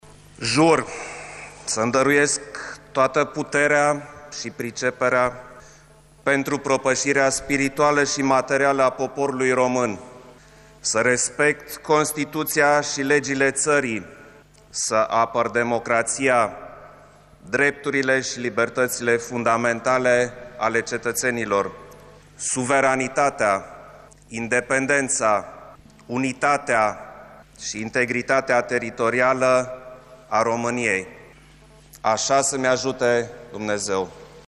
Klaus Iohannis a preluat, la Cotroceni, mandatul de la Traian Băsescu, după ce a depus jurământul de învestitură în funcţie, în şedinţa solemnă comună a Senatului şi Camerei Deputaţilor.
Noul președinte al României a declarat, în discursul rostit în faţa Parlamentului, că este sunt recunoscător şi onorat de încrederea pe care cetăţenii i-au acordat-o alegându-l preşedinte şi a asigurat că va fi preşedintele tuturor românilor.
01-klaus-iohannis-juramant.mp3